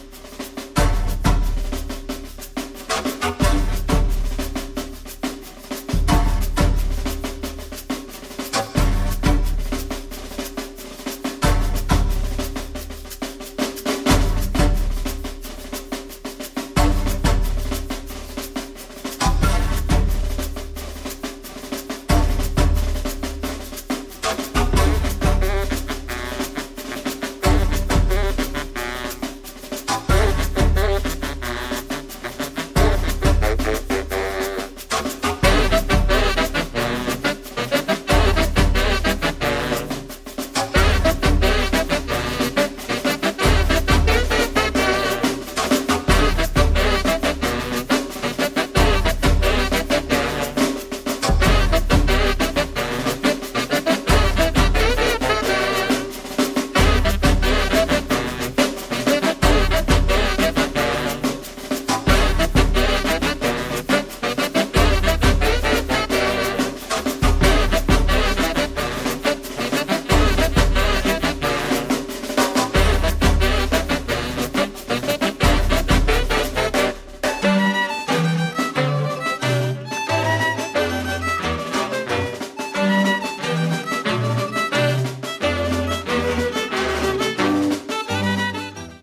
フォルクローレとジャズが交錯した最高の一枚！